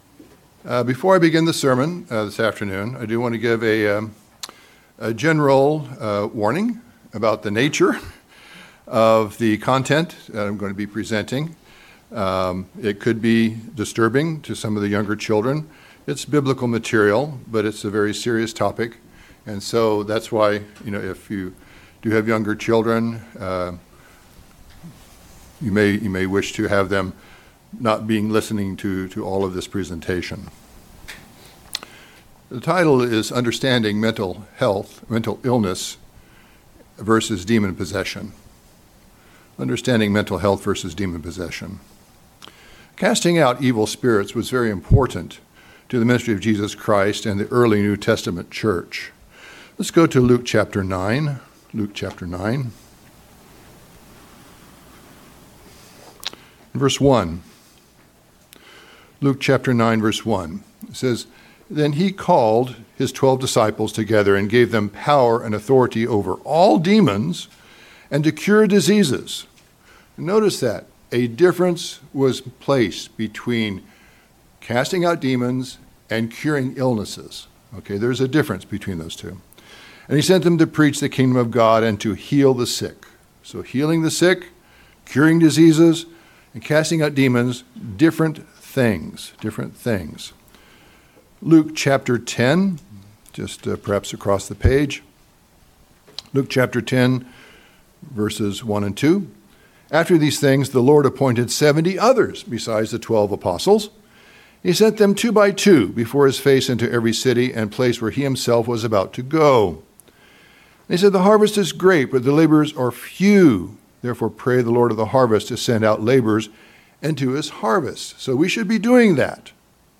There is a difference between mental illness and demon possession. The sermon will examine characteristics of demon manifestation and appropriate responses / preparation.
Given in Northern Virginia